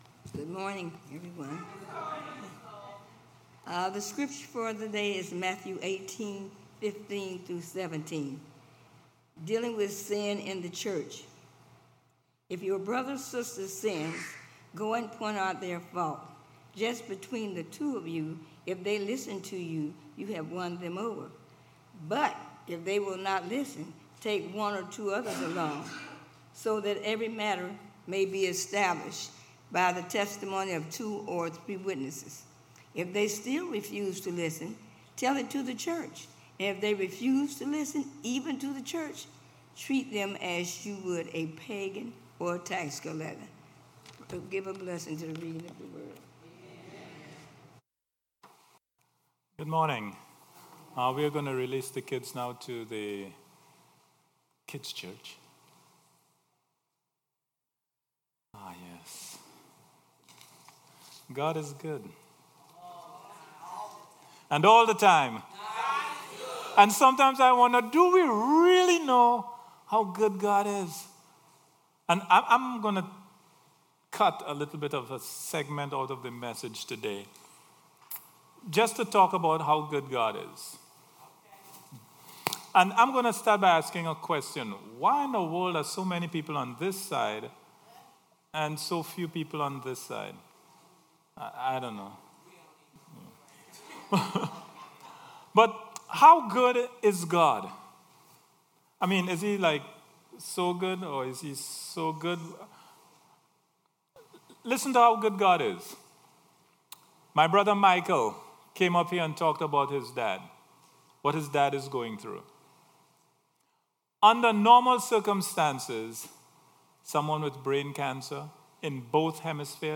Worship Service 9/16/18